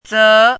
chinese-voice - 汉字语音库
ze2.mp3